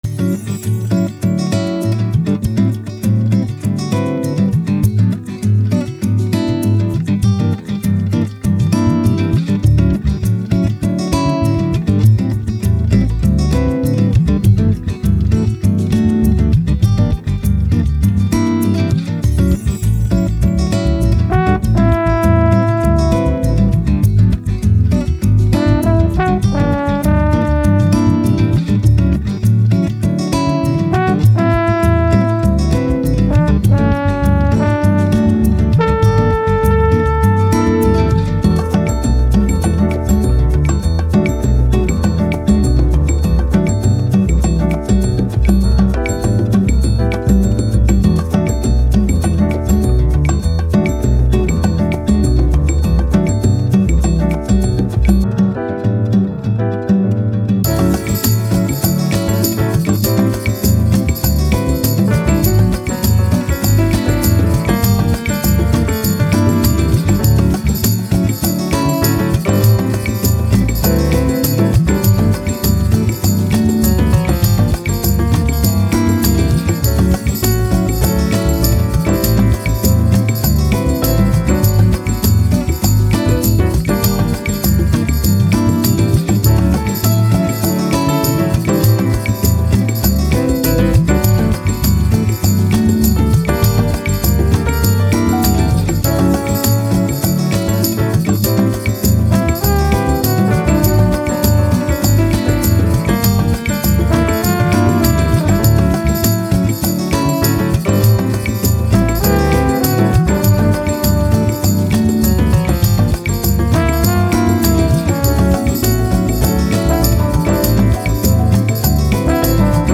Jazz, Bossa Nova, Latin, Joy, Happy